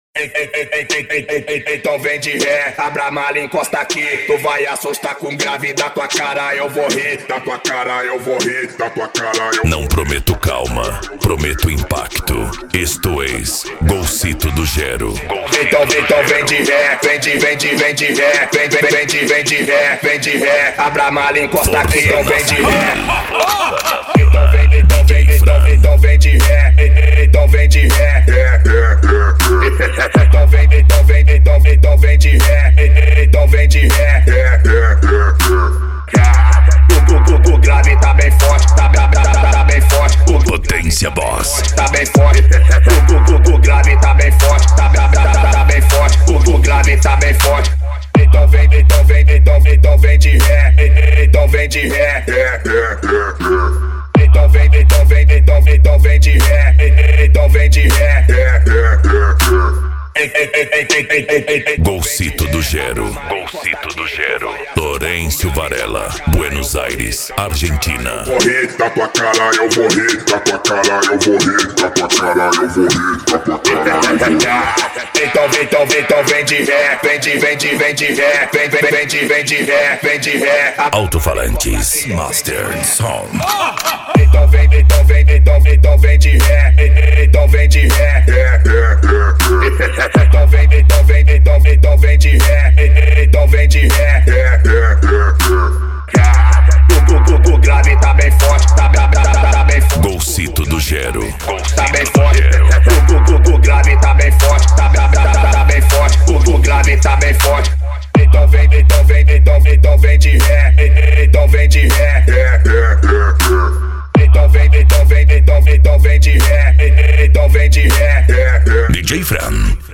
Bass
Racha De Som
Remix